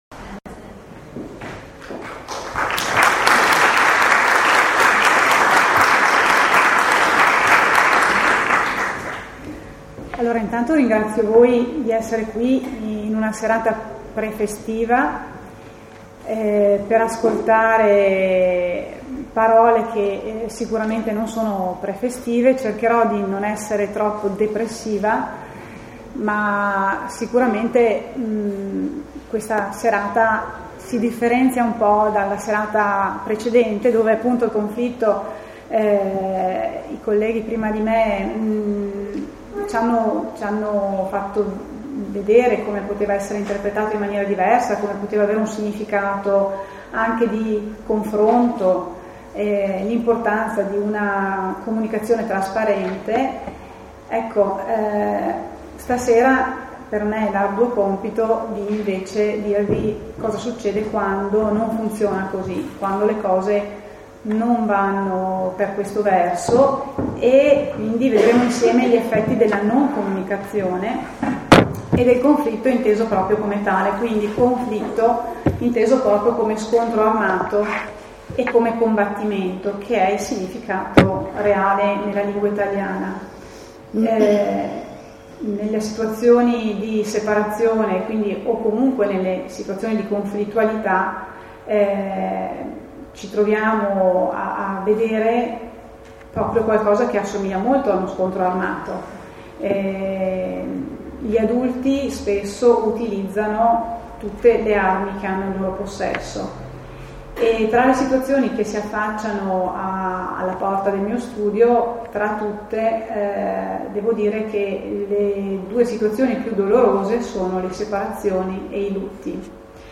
Momento emozionante , intenso e profondo e genitori molto coinvolti ( circa cento presenti ) .